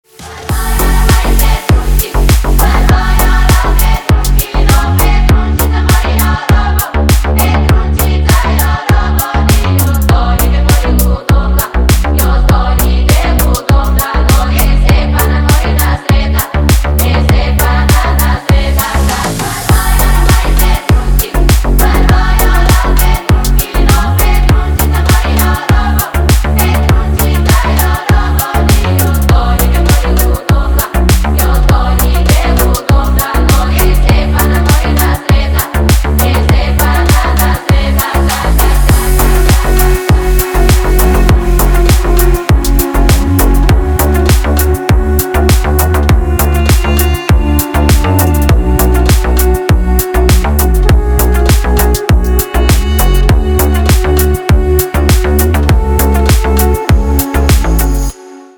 • Песня: Рингтон, нарезка
играет Deep House рингтоны🎙